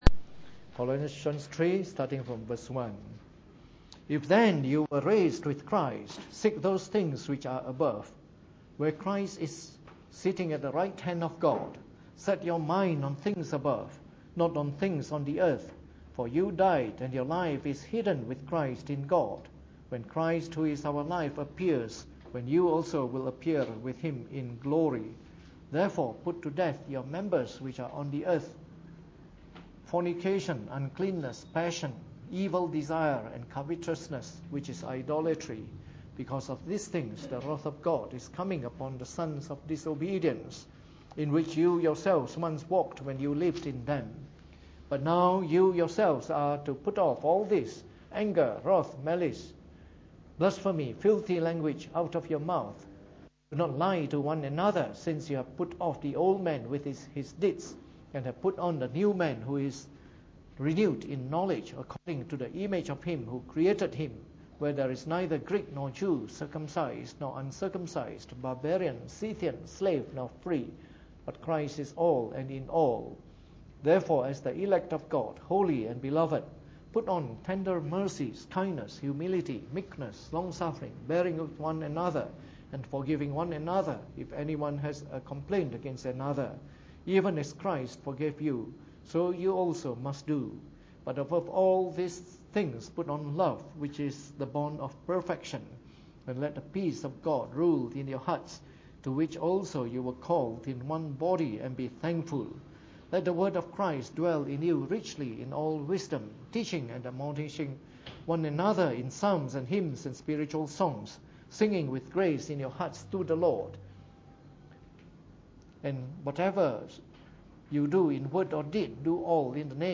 Preached on the 26th of November 2014 during the Bible Study, the final talk in our series on Eschatology.